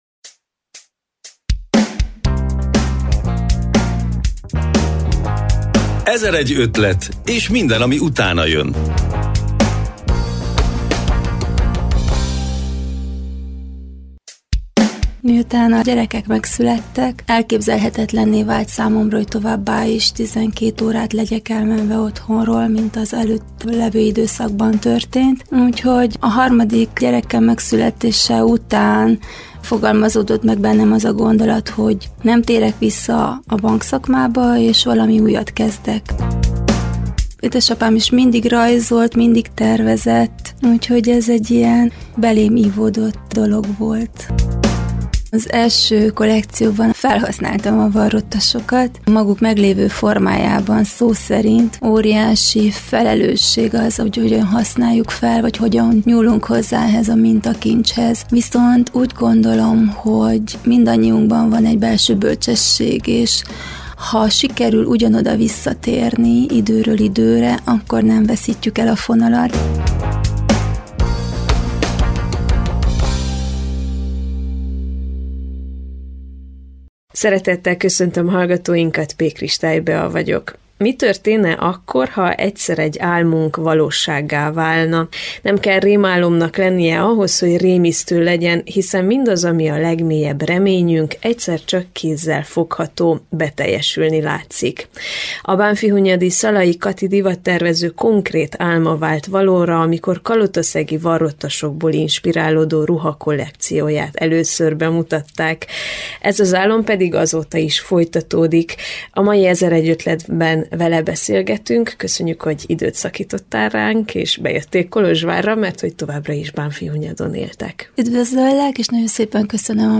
Az utolsó simítások közepett látogatott el a Kolozsvári Rádió stúdiójába.